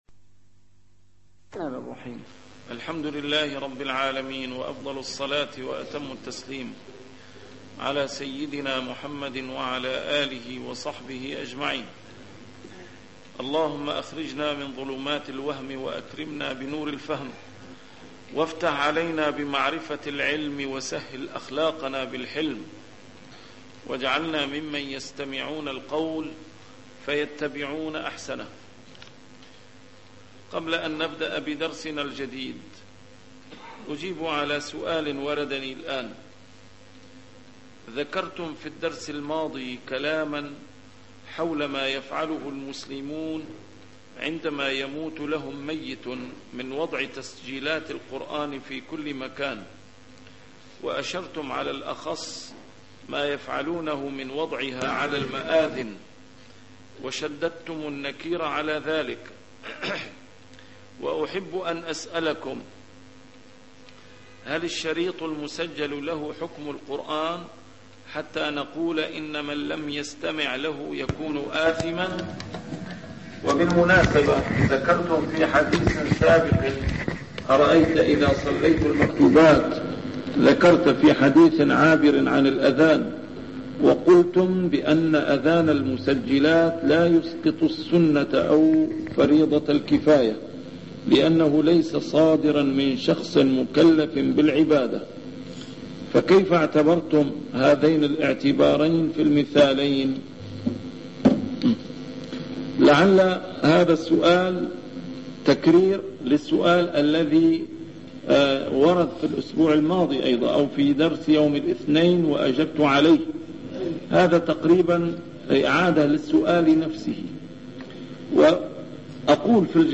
A MARTYR SCHOLAR: IMAM MUHAMMAD SAEED RAMADAN AL-BOUTI - الدروس العلمية - شرح الأحاديث الأربعين النووية - بداية شرح الحديث الرابع والعشرون: حديث أبي ذر الغفاري (يا عبادي إني حرَّمتُ الظلم على نفسي) 77